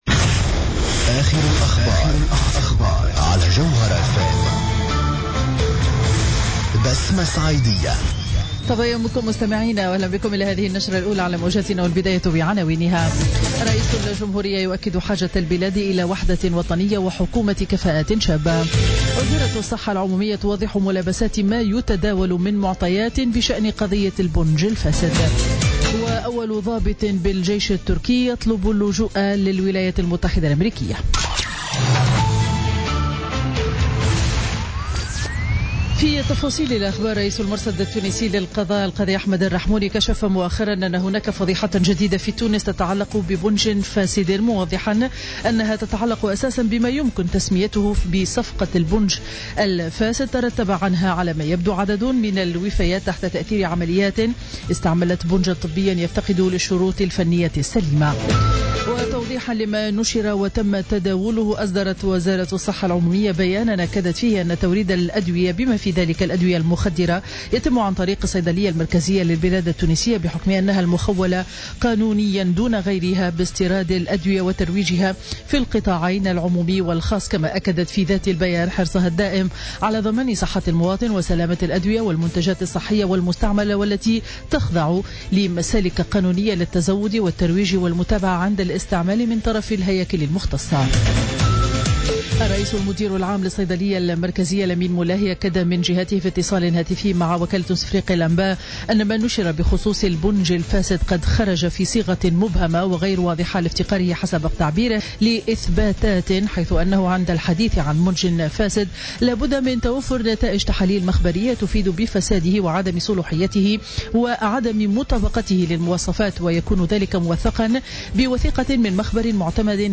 نشرة أخبار السابعة صباحا ليوم الاربعاء 10 أوت 2016